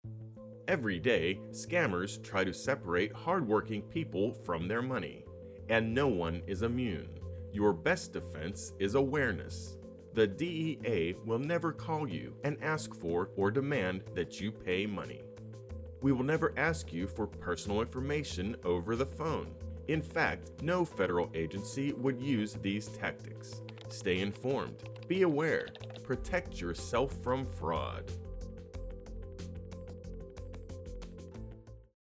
The DEA released a new public service announcement to warn citizens of this scam.
PSA_audio-only_30sec.mp3